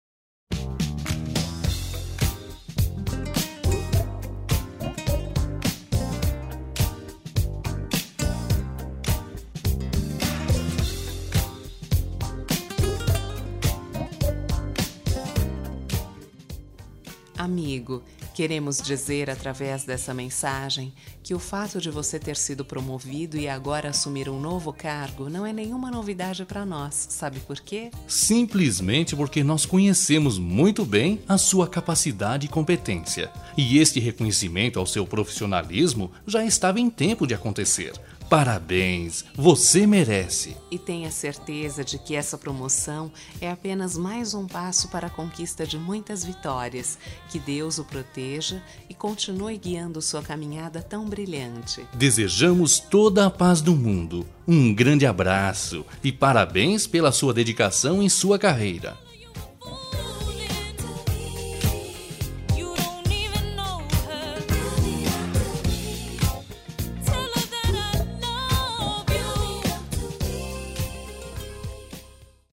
Duas Vozes